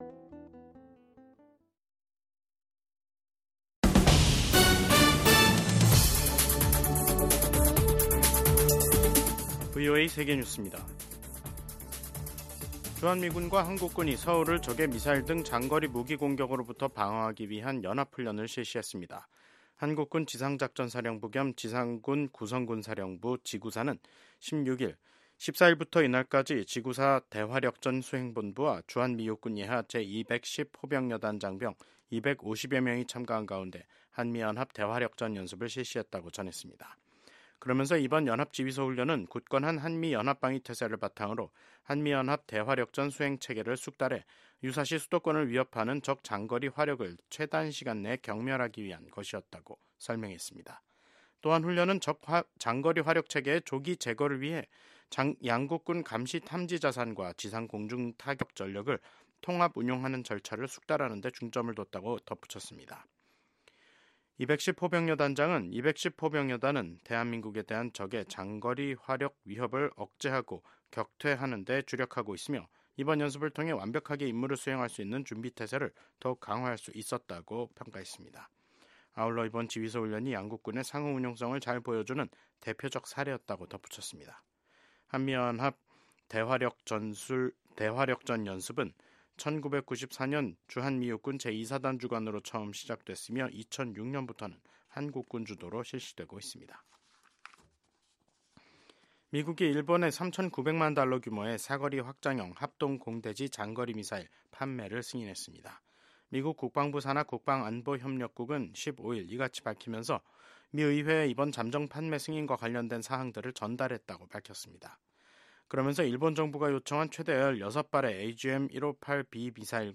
VOA 한국어 간판 뉴스 프로그램 '뉴스 투데이', 2025년 1월 16일 3부 방송입니다. 조 바이든 미 행정부와 윤석열 한국 정부가 핵협의그룹(NCG) 출범 등으로 강화시킨 미한 확장억제가 도널드 트럼프 새 행정부 아래에서도 그 기조가 유지될 지 주목됩니다. 미국 국무부는 한국이 정치적 혼란 속에서 민주주의의 공고함과 회복력을 보여주고 있다고 말했습니다.